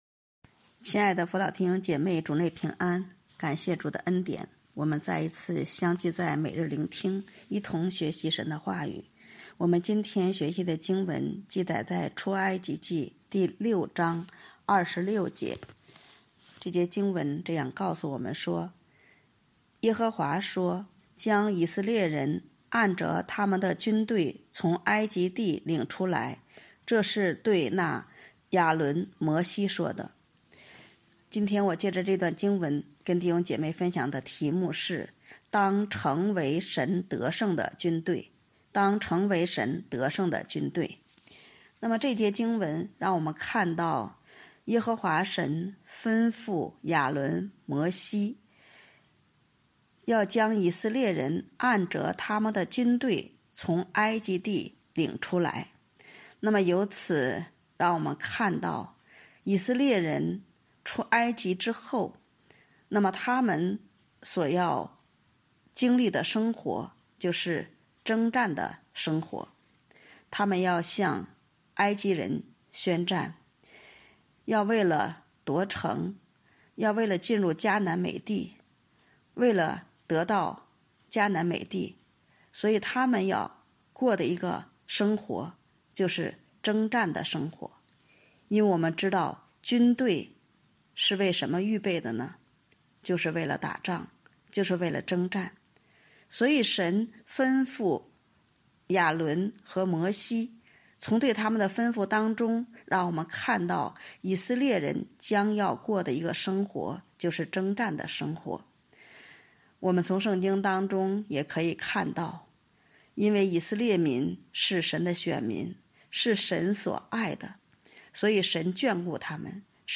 当成为神得胜的军队 | 北京基督教会海淀堂